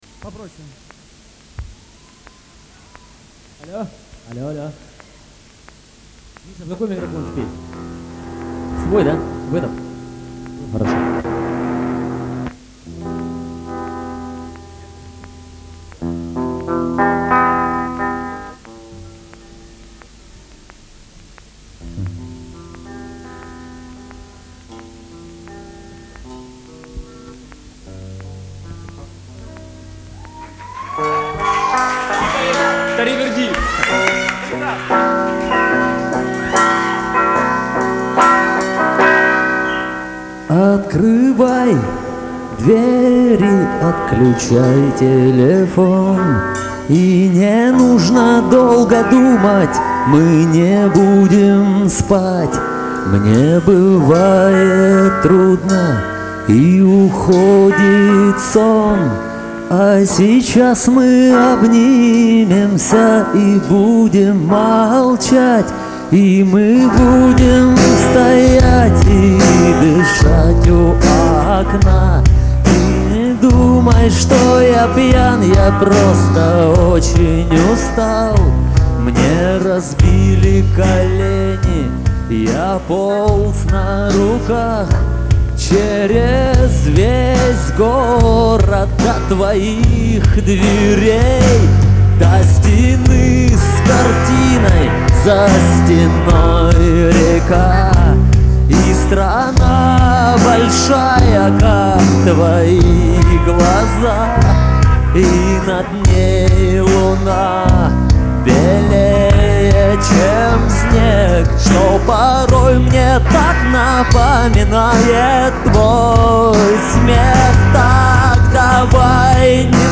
вокал, акустика